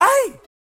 SouthSide Chant (58).wav